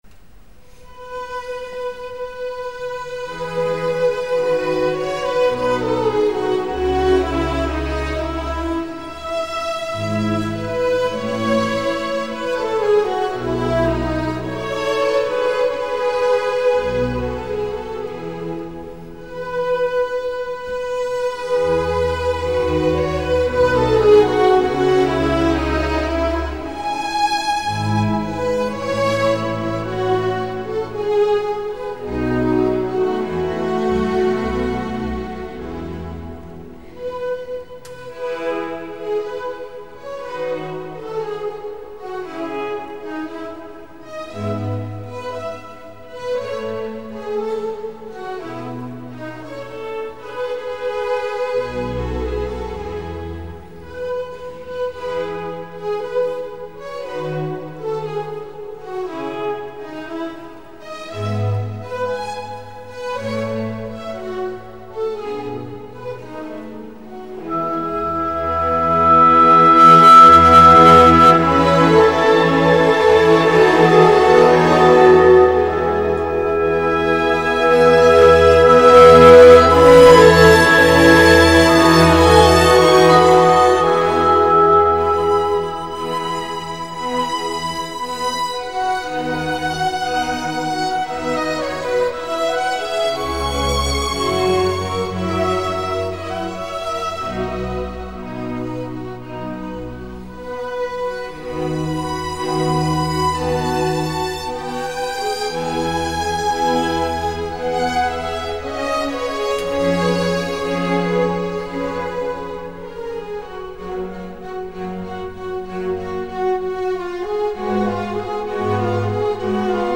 Adagio (stessa orch. Riga, Cattedrale, 28/4/03)